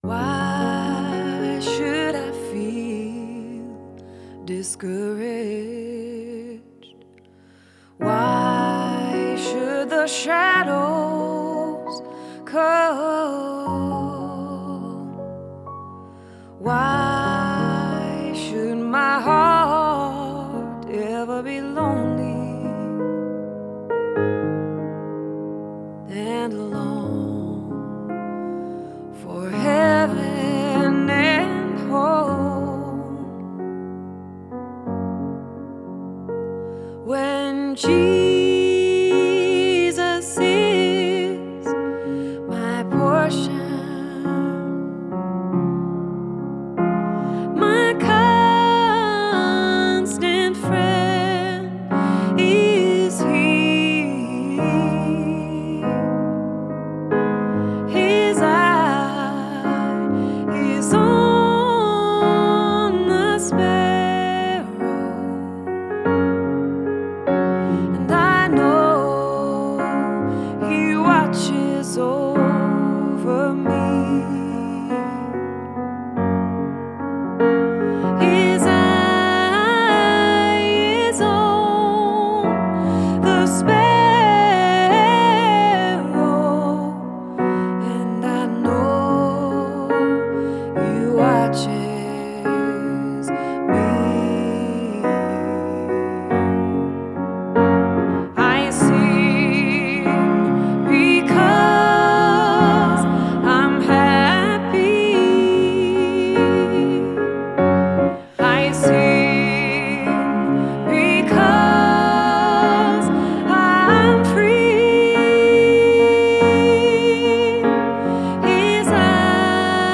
Recorded at Studio150 in Burlington, VT.